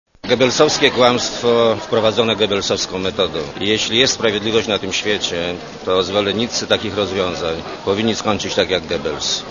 Mówi Leszek Miller